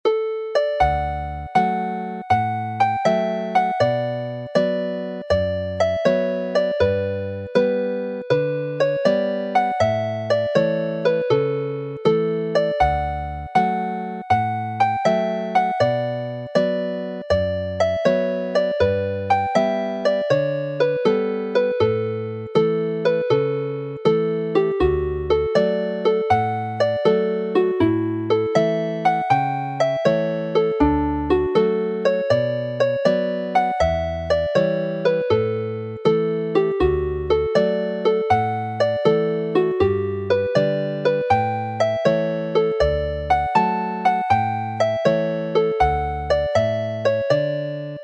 Play the hornpipe slowly